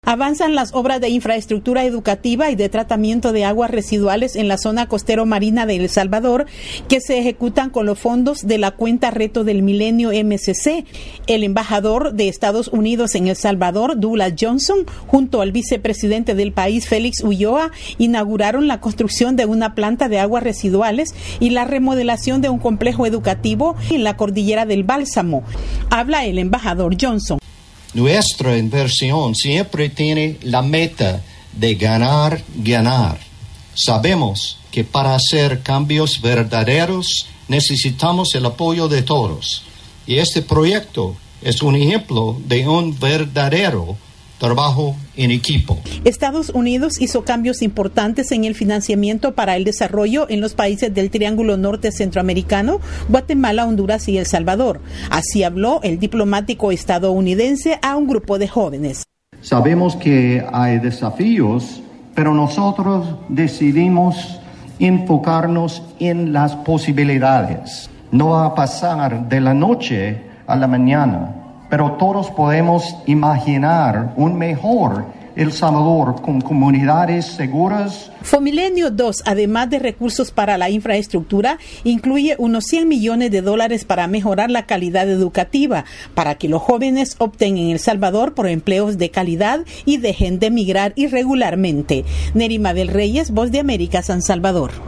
VOA: Informe de El Salvador